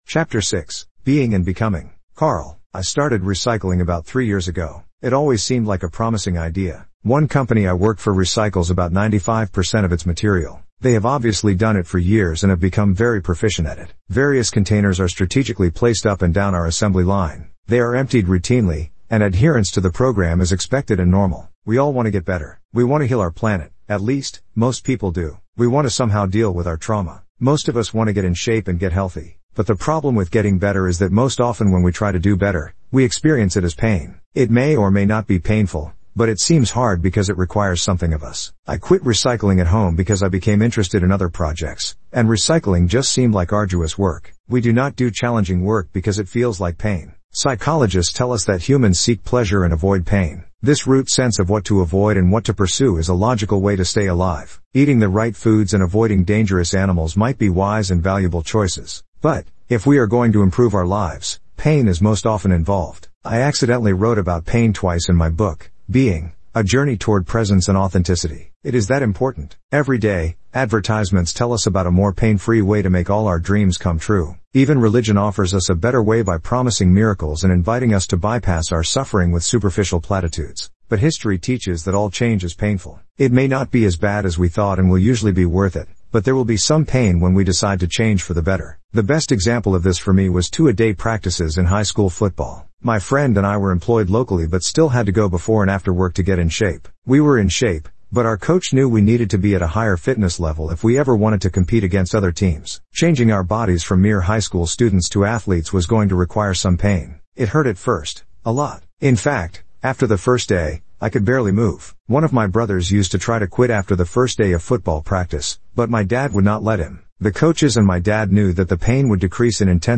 Listen to this blog – AI-Generated